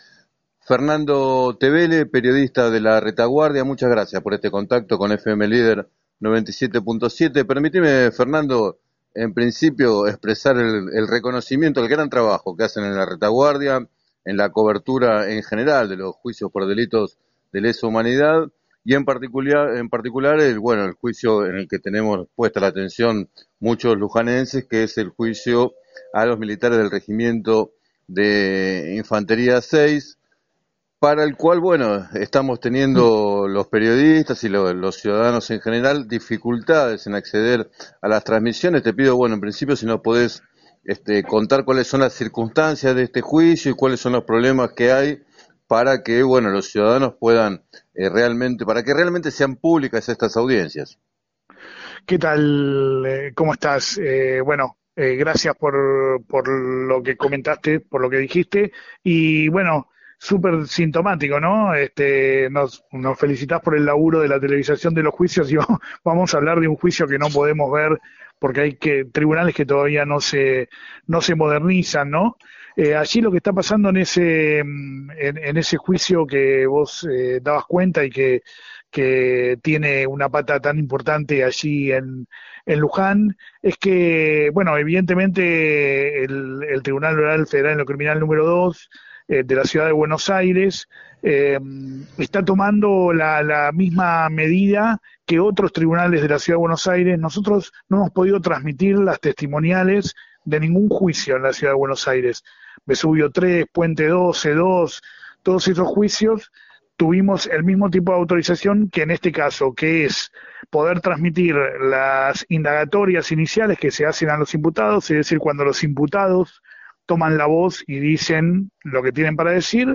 En declaraciones al programa “7 a 9” de FM Líder 97.7